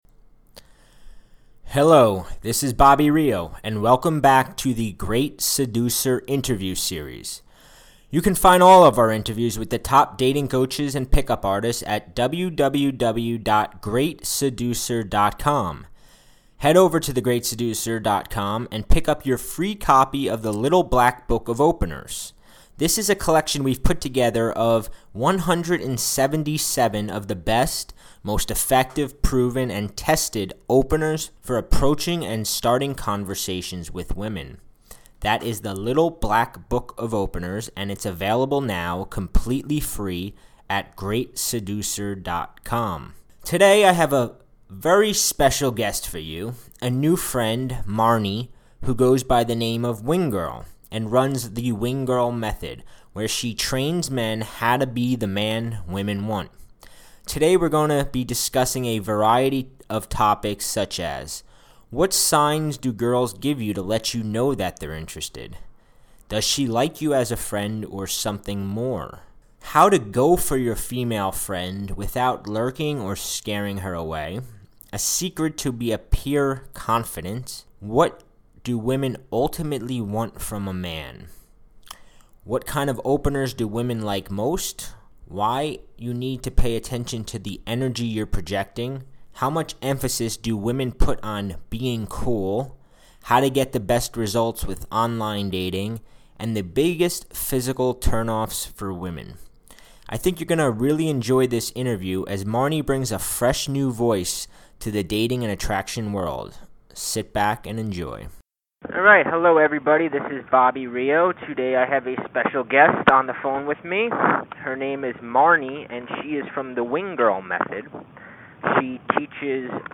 wingirl-interview.mp3